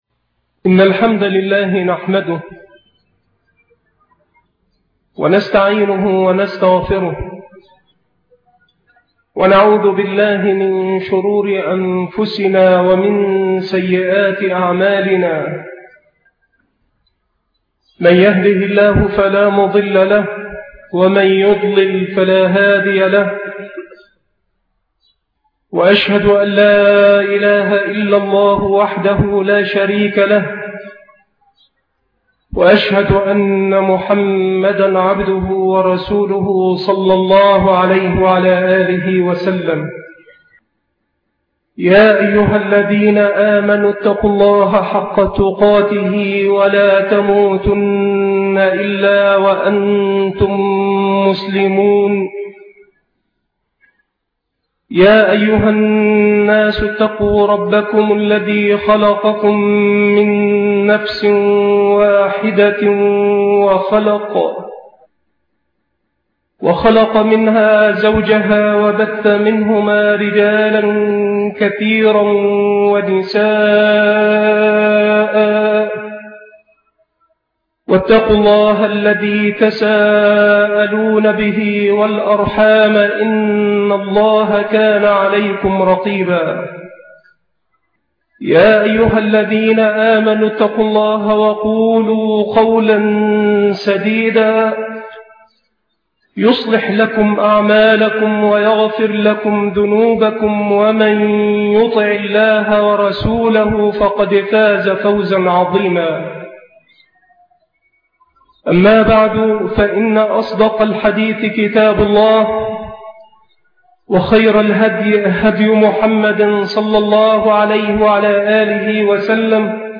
محاضرة تفسير قوله تعالى ( يَا أَيُّهَا الرَّسُولُ بَلِّغْ مَا أُنزِلَ إِلَيْكَ مِن رَّبِّكَ......)